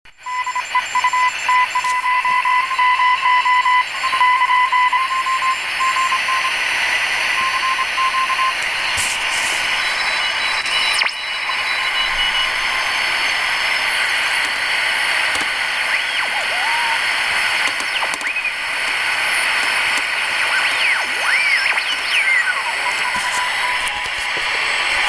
Увы, помехи от радиовещательных станций, всё равно имеются.
Я покрутил, записи прилагаю, можно уменьшить помехи, заодно с чувствительностью...